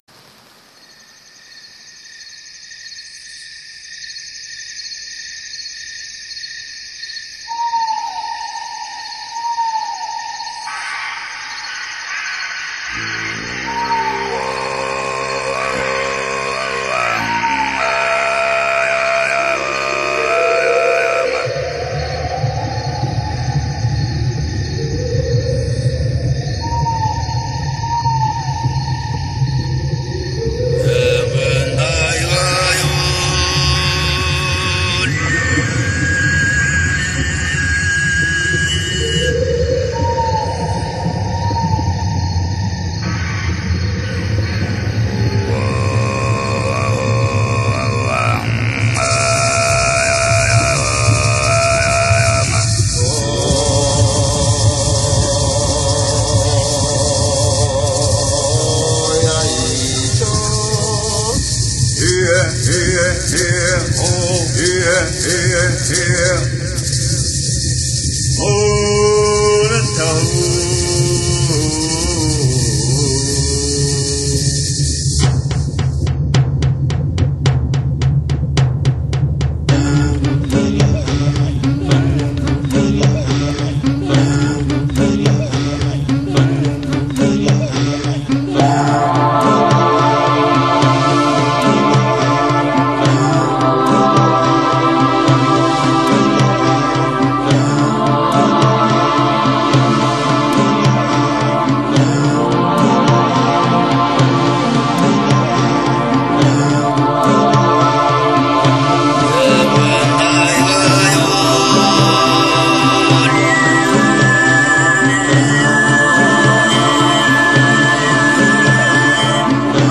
音乐类别 ： 新世纪音乐
专辑特色 ： 山川大地的脉动
透过变幻多端的电子合成，营造了缤纷多彩的效果。
为了使音乐更加通俗易懂，唱片的制作中加入了大量实地采样的真实效果，像真度之高堪称离奇，犹如置身深山老林，身临其境。